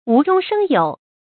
注音：ㄨˊ ㄓㄨㄙ ㄕㄥ ㄧㄡˇ
讀音讀法：